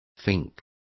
Complete with pronunciation of the translation of finks.